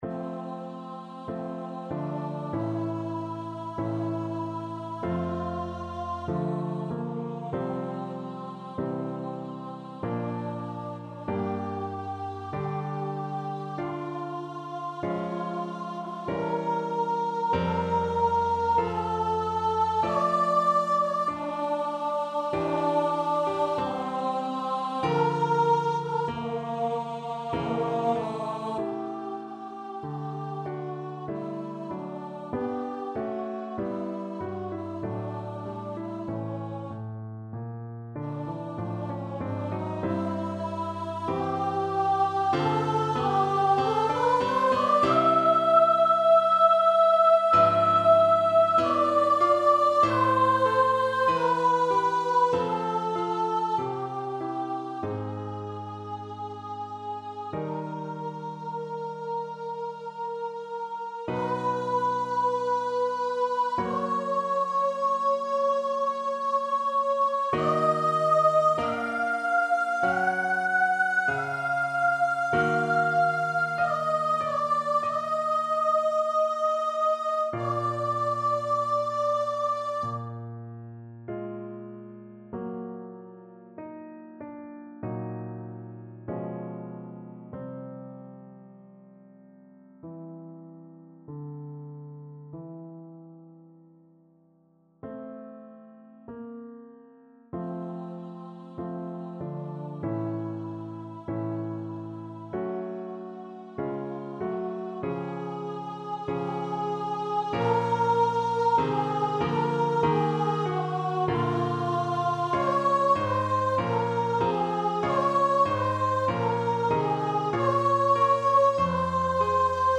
2/2 (View more 2/2 Music)
F4-F#6
~ = 96 Alla breve. Weihevoll.
Voice  (View more Intermediate Voice Music)
Classical (View more Classical Voice Music)